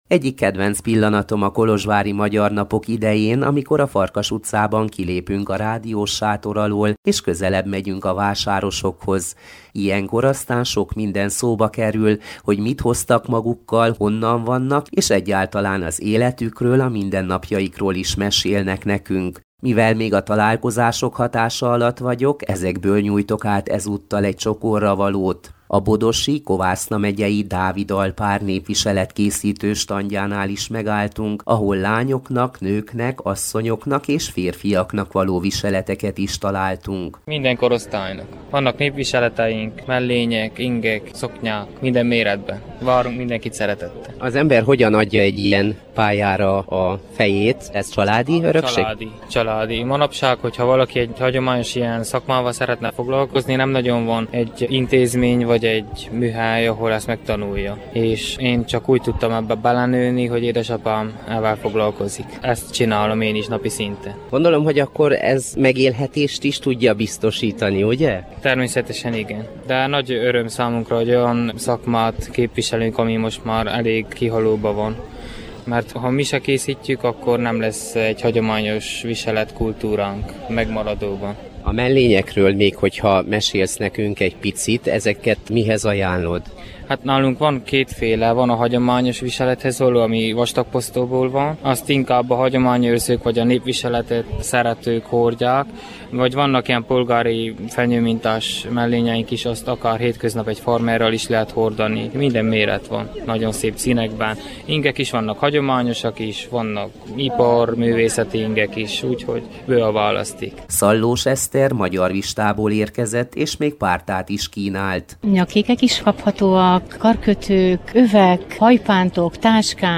A Kolozsvári Magyar Napok találkozásai ihlették ezúttal a Kanapéfilozófiát.